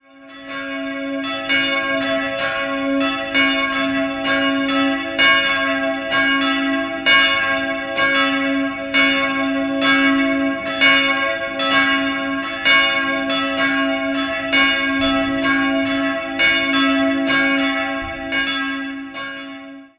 2-stimmiges Kleine Terz-Geläute: cis''-e'' Die Glocken wurden 1960 von Friedrich Wilhelm Schilling in Heidelberg gegossen. Die größere wiegt 290 kg, die kleinere 169 kg.